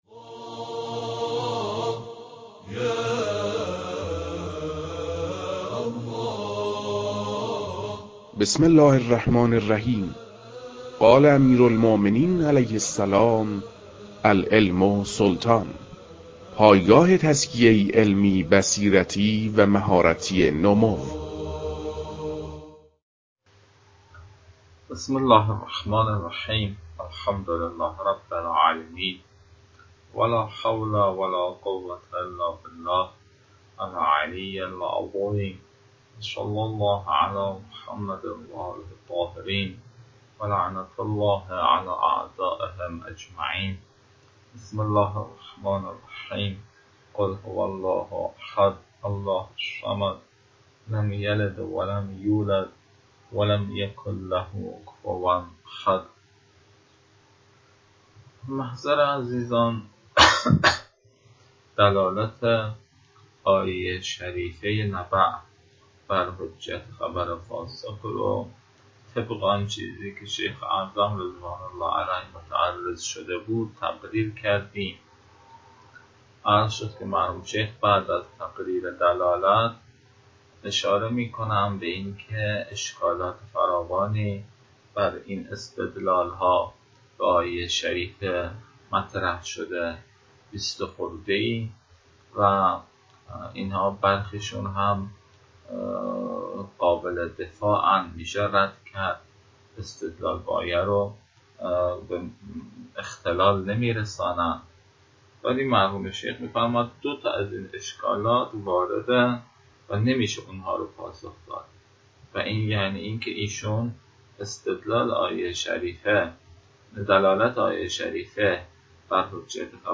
در این بخش، فایل های مربوط به تدریس مبحث رسالة في القطع از كتاب فرائد الاصول